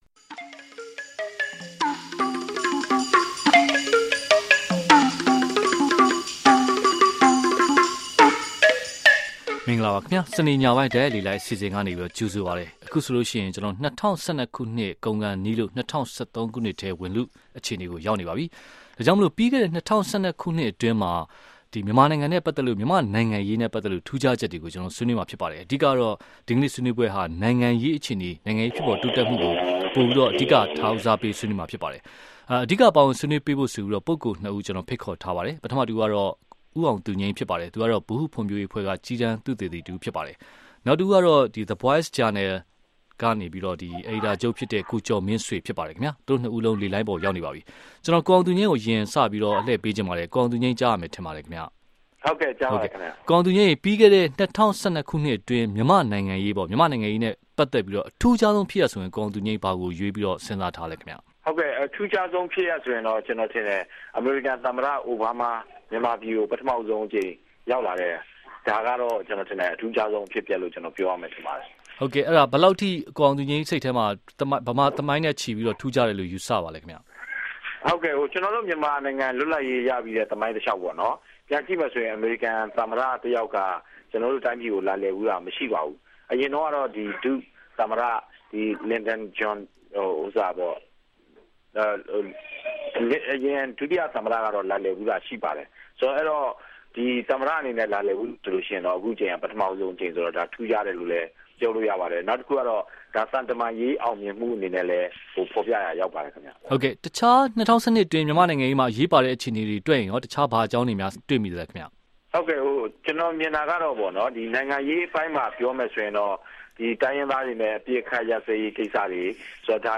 ၂၉ ဒီဇင်ဘာ ၂၀၁၂။ ၂၀၁၂ ခုနှစ်ကုန်ဆုံးလို့ ၂၀၁၃ ခုနှစ်ထဲ ဝင်ရောက်လာပါပြီ။ ၂၀၁၂ ခုနှစ်အတွင်း မြန်မာ့နိုင်ငံရေး အတတ်အကျနဲ့၊ အထင်ရှားဆုံး နိုင်ငံရေးဖြစ်ရပ်တွေကို အခု တိုက်ရိုက်လေလှိုင်းမှာ ဆွေးနွေးထားပါတယ်။ ကြားဖြတ်ရွေးကောက်ပွဲ၊ သမတအိုဘားမား မြန်မာပြည်ခရီးစဉ်နဲ့ အမေရိကန်-မြန်မာ ဆက်ဆံရေး၊ သတင်းမီဒီယာ...